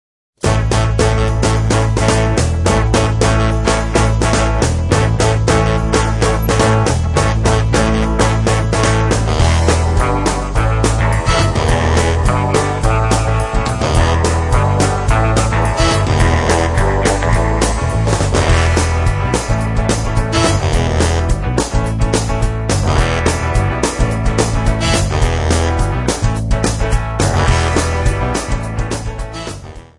Jolly contemporary religious Songs sung by Children.